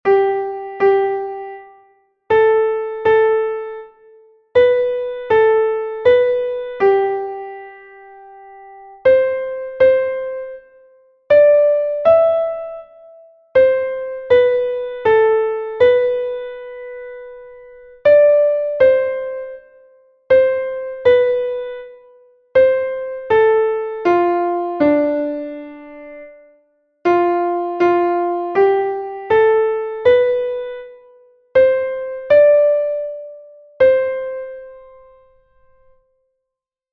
Here there are four 6/8 time signature exercises.